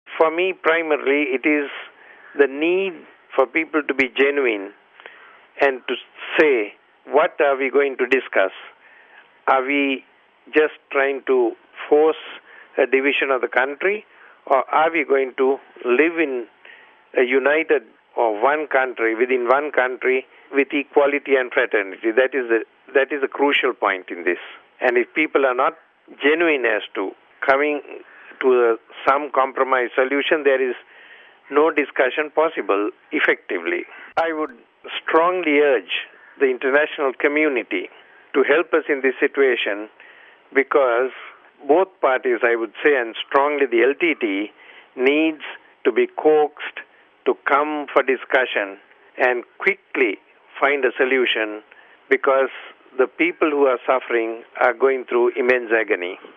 (13 May 06 - RV) As the fragile peace between the government of Sri Lanka and the island nation's Tamil Tiger rebels begins to break down, the Archbishop of Colombo, Oswald Gomis tells us what he feels are the main obstacles to peace...